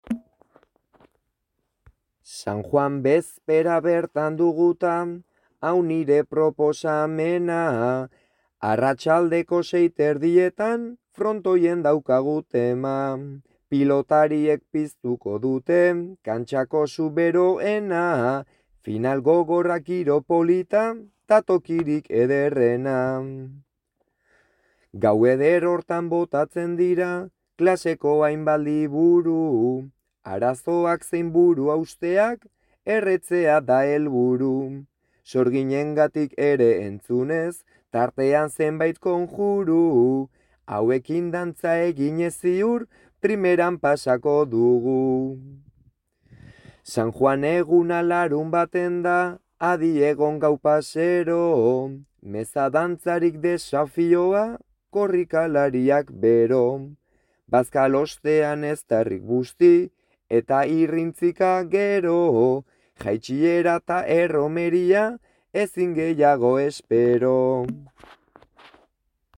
bertso sorta